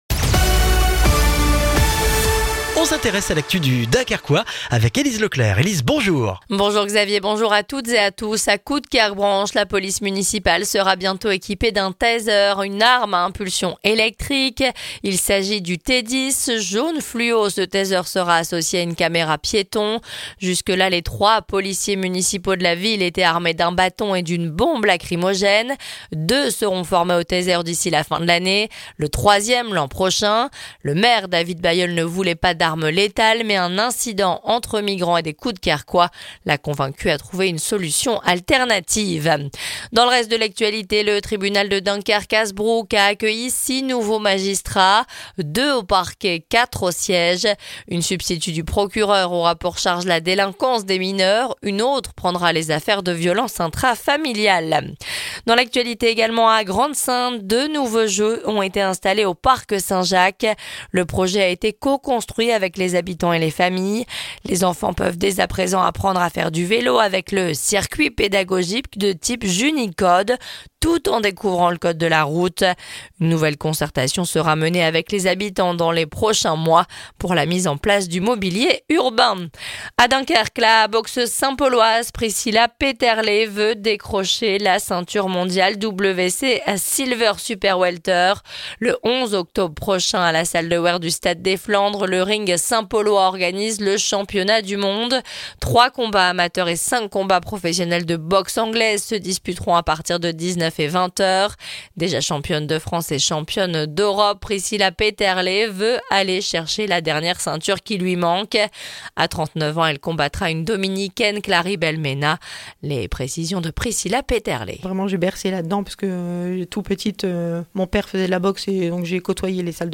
Le journal du mercredi 2 octobre dans le Dunkerquois